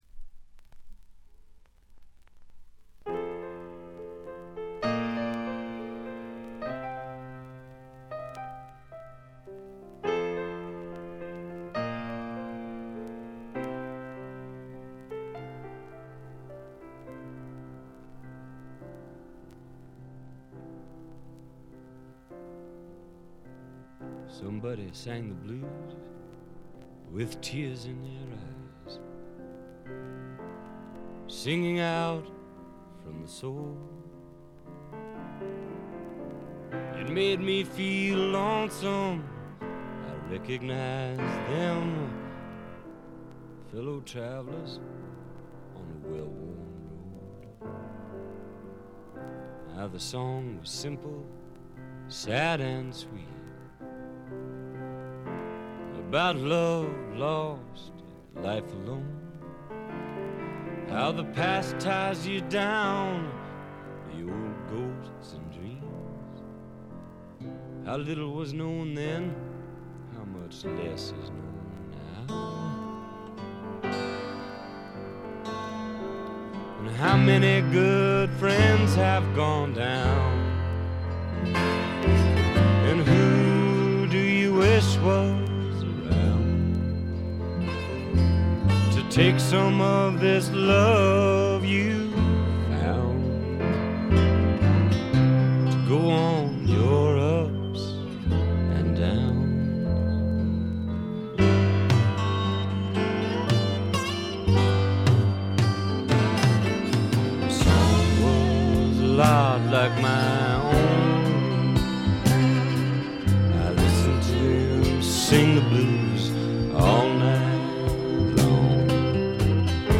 これ以外はわずかなチリプチ程度。
試聴曲は現品からの取り込み音源です。